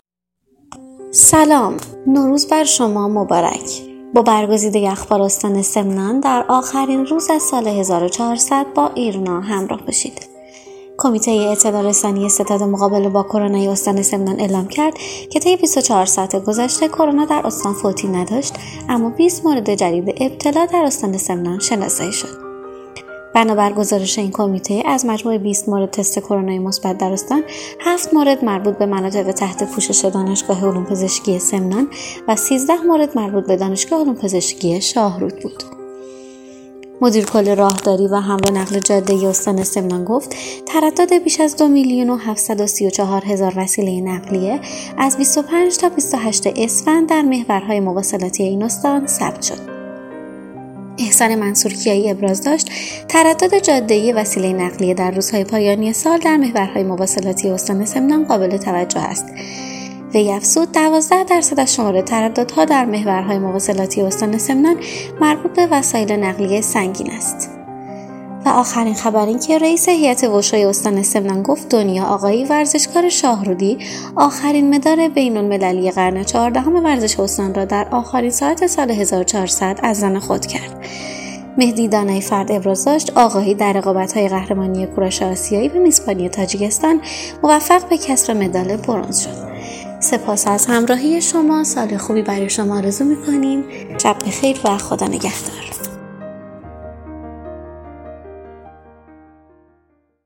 صوت | اخبار شبانگاهی ۲۹ اسفند استان سمنان